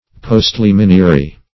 Search Result for " postliminiary" : The Collaborative International Dictionary of English v.0.48: Postliminiary \Post`li*min"i*a*ry\, a. Pertaining to, or involving, the right of postliminium.